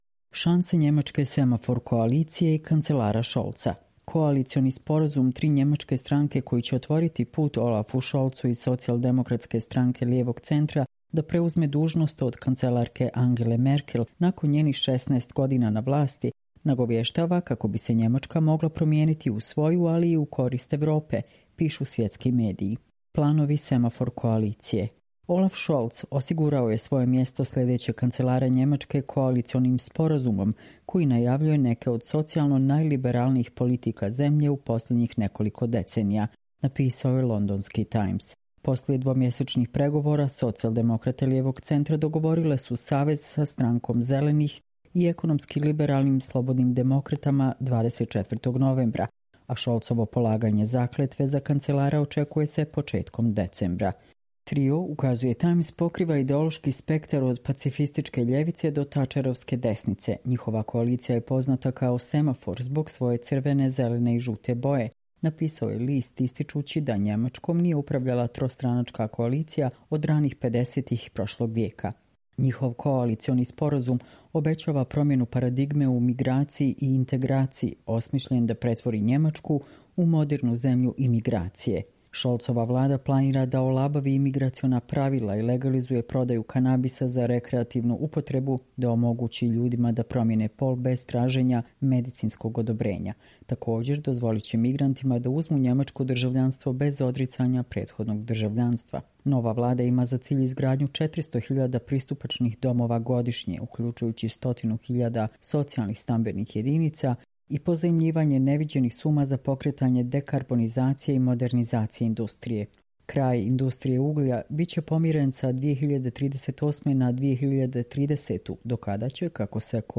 Čitamo vam: Šanse njemačke 'semafor' koalicije i kancelara Scholza